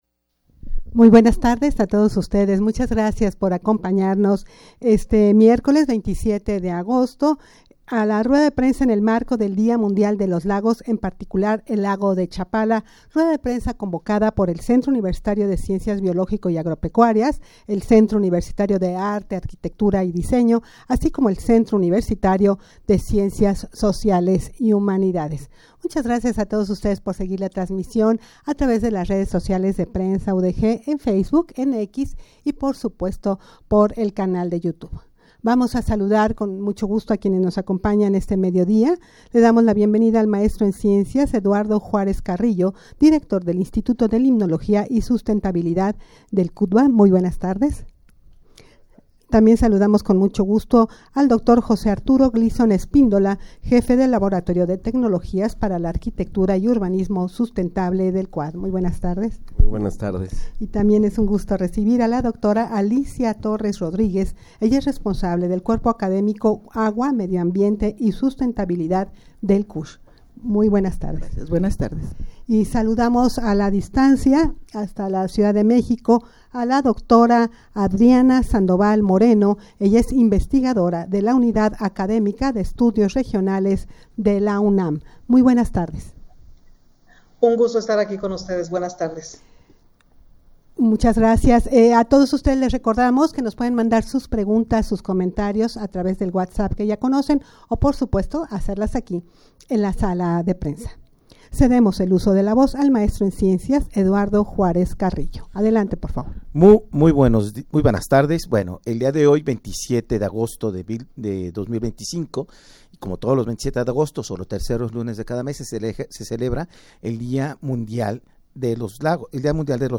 rueda-de-prensa-en-el-marco-del-dia-mundial-de-los-lagos-en-particular-el-lago-de-chapala.mp3